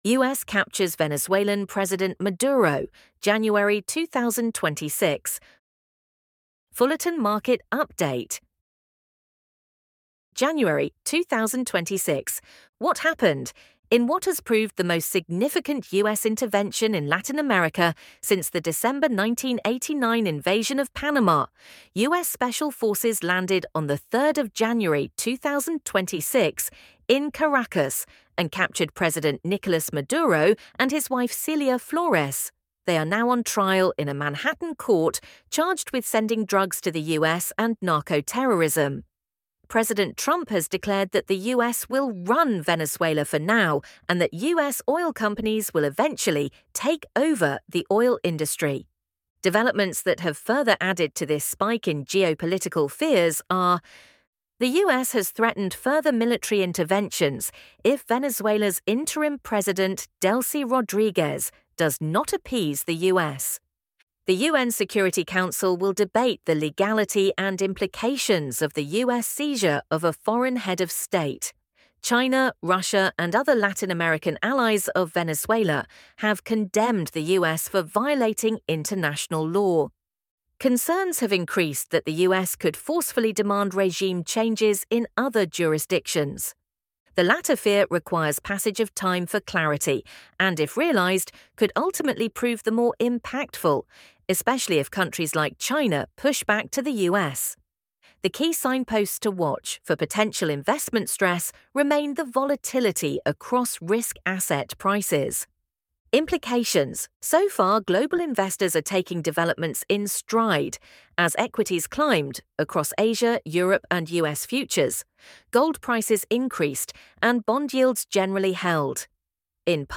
ElevenLabs_US_captures_Venezuelan_President_Maduro_January_2026.mp3